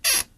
bed_creaking_SFX.ogg